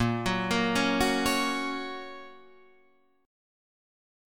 A# Minor Sharp 5th